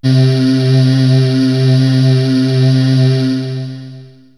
VOX_CHORAL_0005.wav